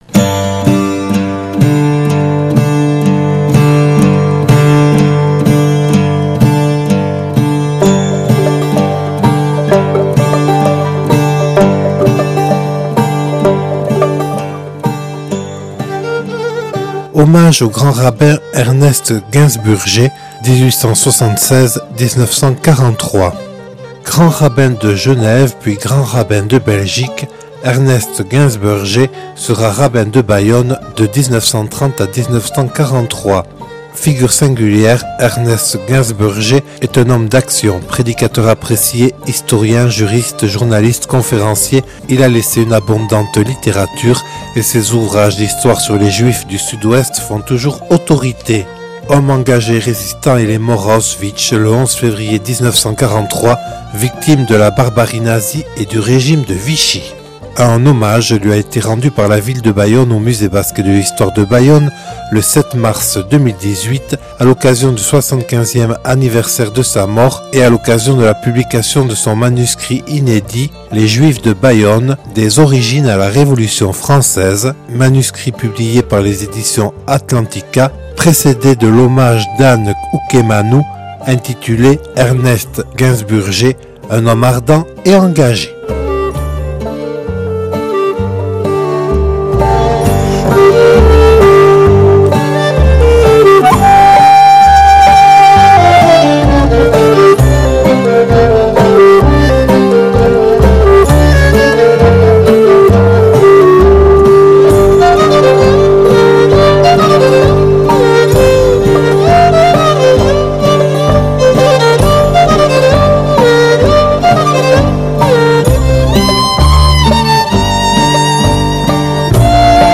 (Enregistré le 07/03/2018 au Musée Basque et de l’Histoire de Bayonne).